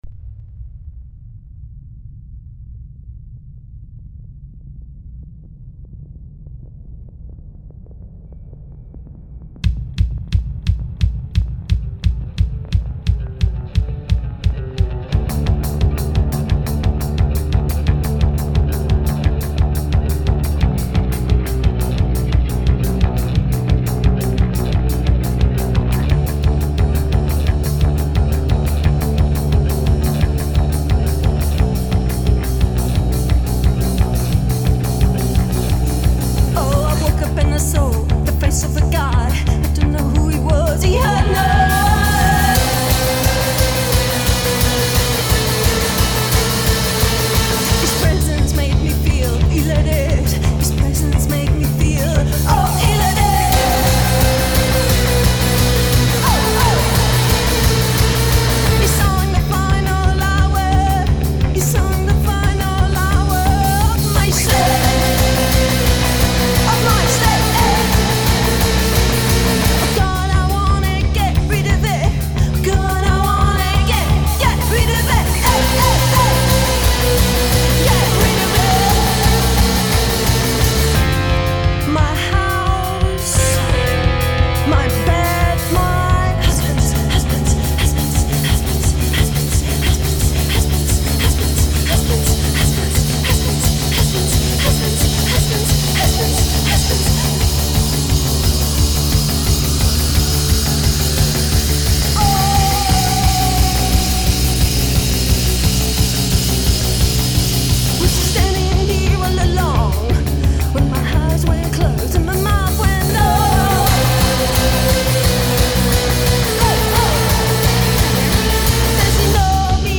London she punks